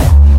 VEC3 Bassdrums Dirty 25.wav